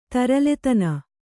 ♪ taraletana